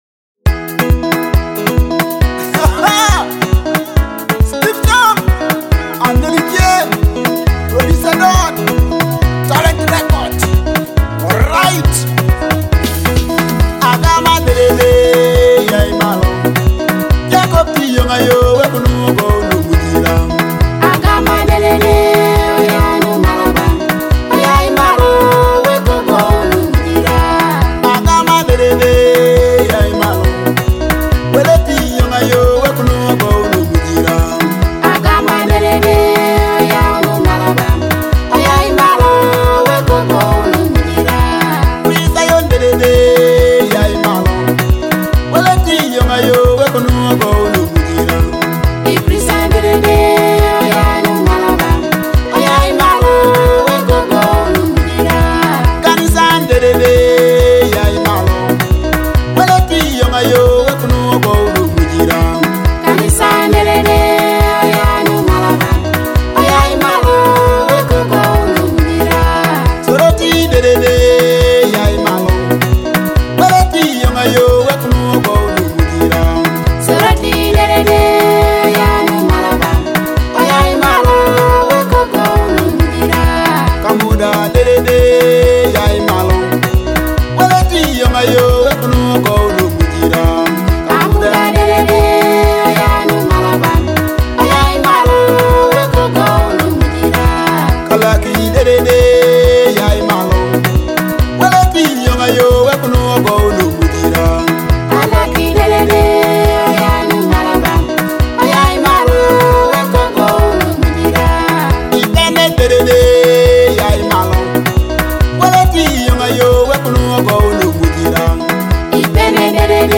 a powerful Teso gospel hit from Uganda.
a soul-stirring gospel hit